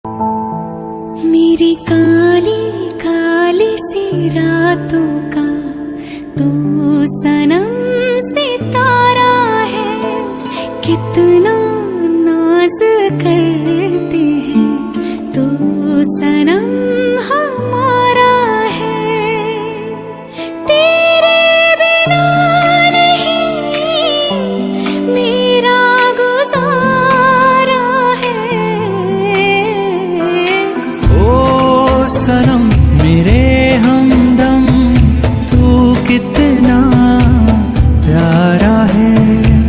Flute Music Ringtone